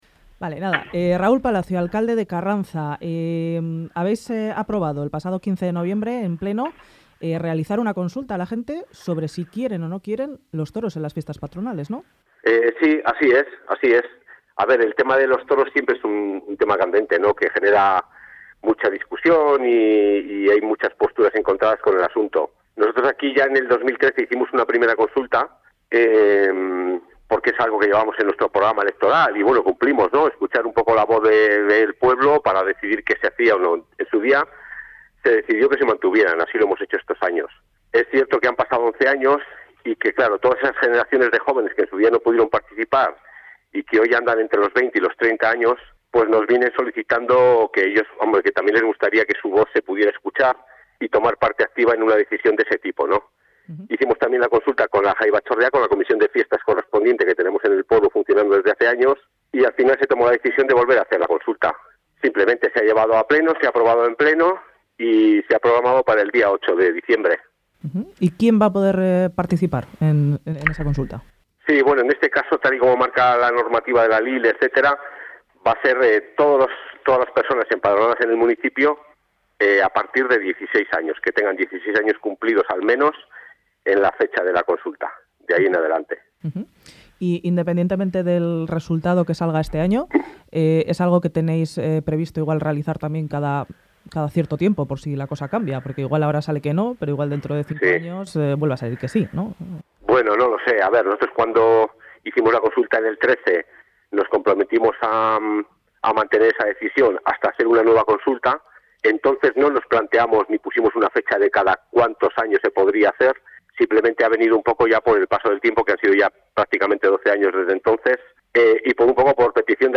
Raul Palacio, alcalde: "En el 2013 hicimos una primera consulta.